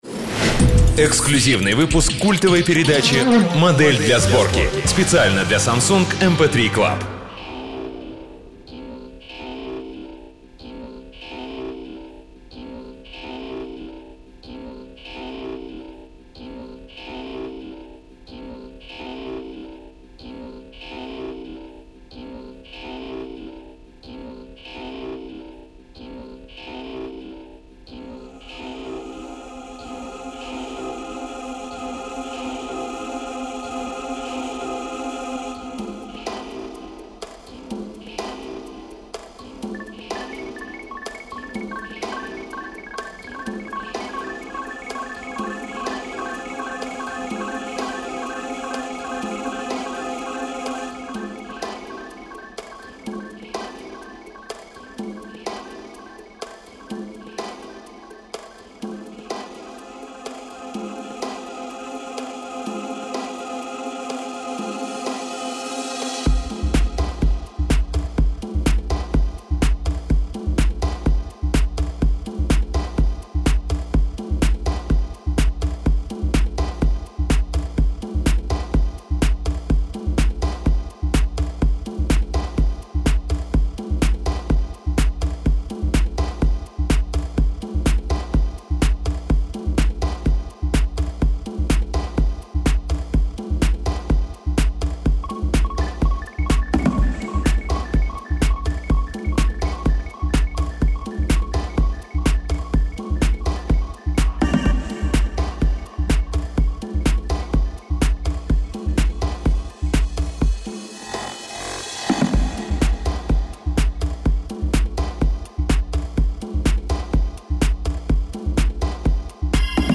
Аудиокнига Евгений Гаркушев — Пирамиды